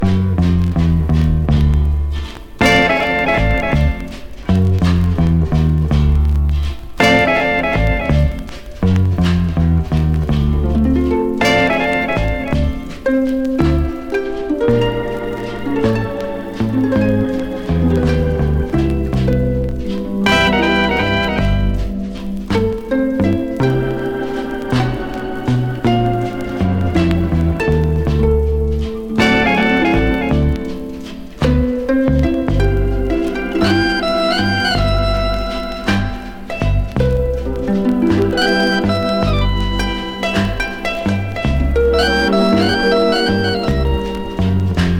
ハープの音ということもありますが、楽曲の中で響くきらめく演奏がたまりません。
Jazz, Pop, Easy Listening　USA　12inchレコード　33rpm　Stereo